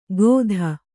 ♪ gōdha